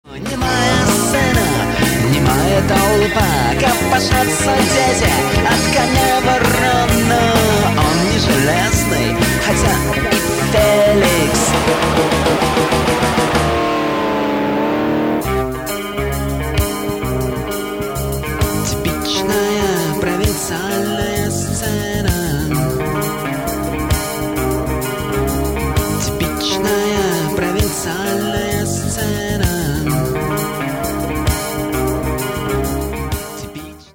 Альтернативная (2891)